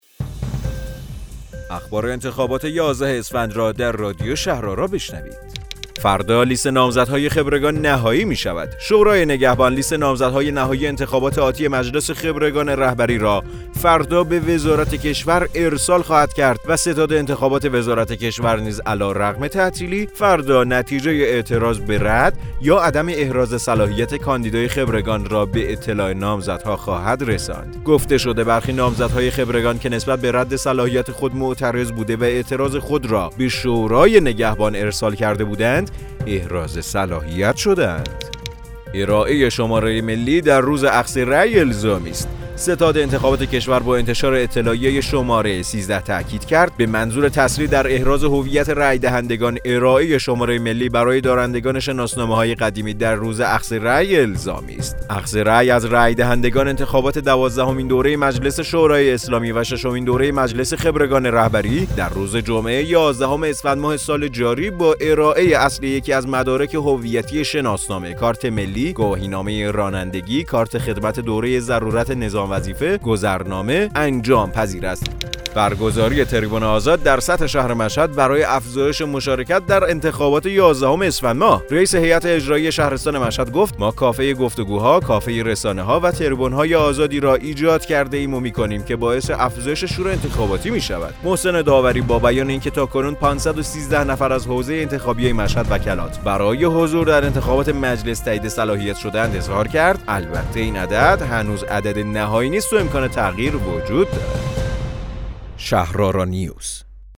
رادیو شهرآرا، پادکست خبری انتخابات یازدهم اسفندماه است.